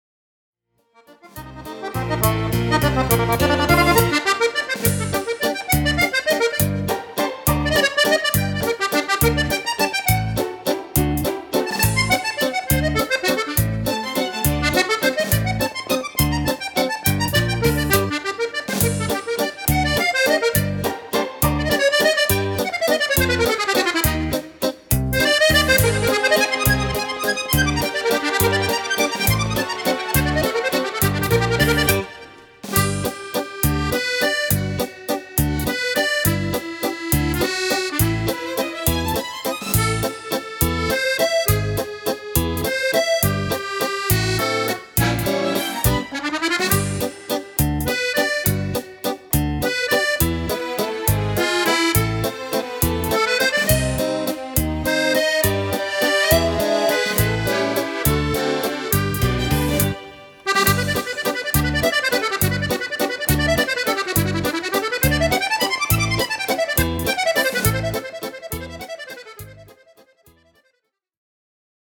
Valzer
Fisarmonica
Strumento Fisarmonica (e Orchestra)